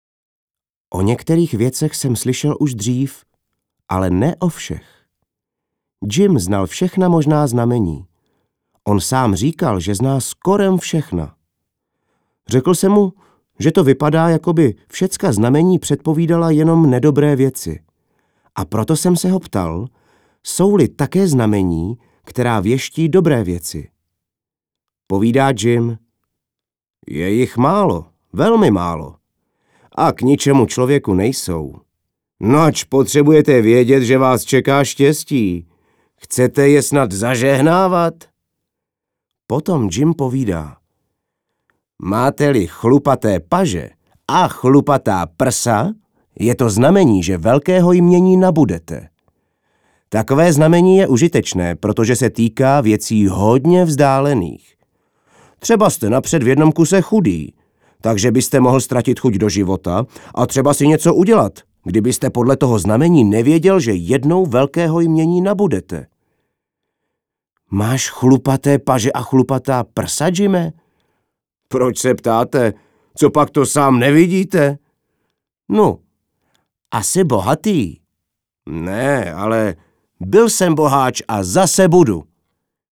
Audioknihy: